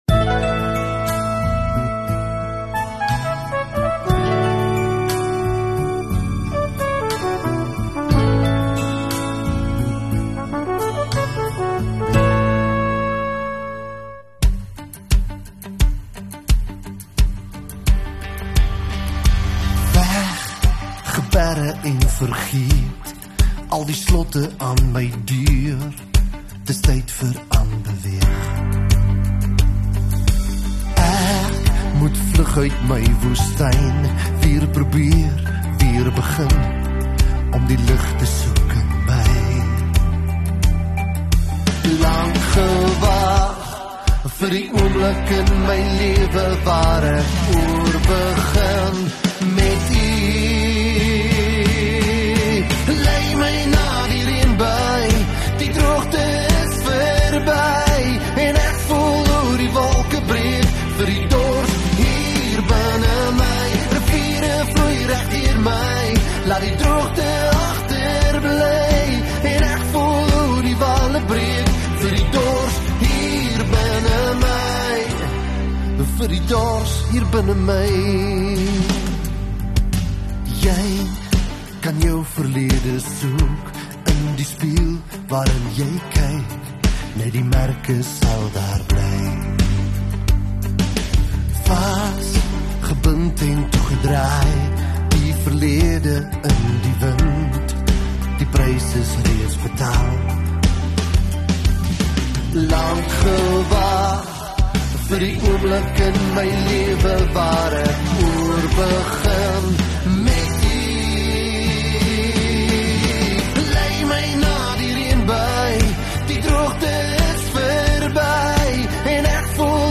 24 Sep Sondagaand Erediens